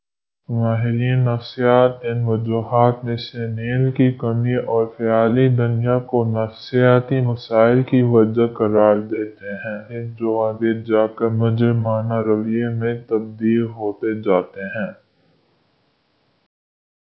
deepfake_detection_dataset_urdu / Spoofed_TTS /Speaker_17 /101.wav